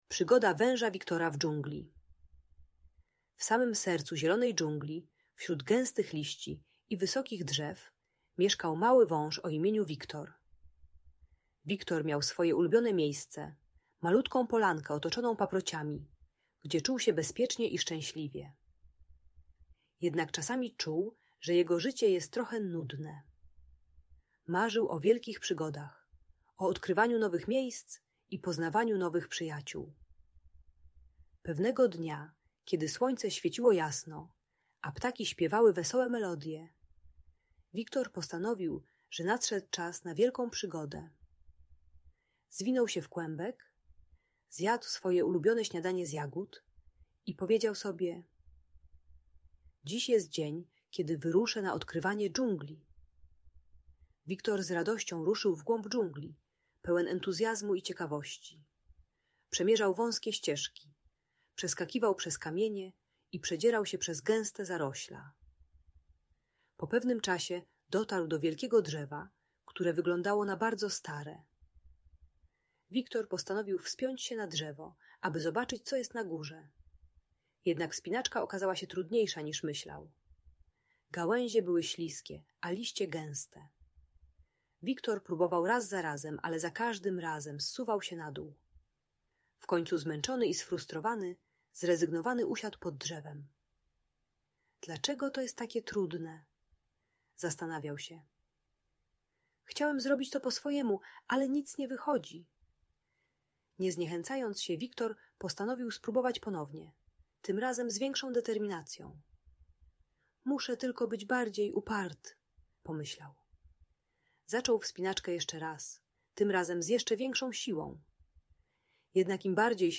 Przygoda Węża Wiktora - Bunt i wybuchy złości | Audiobajka